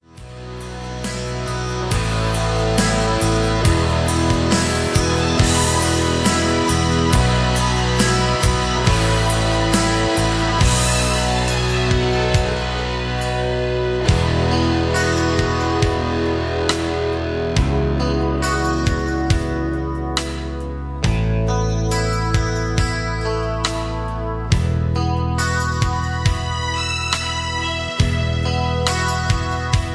karaoke, studio tracks, sound tracks, backing tracks, rock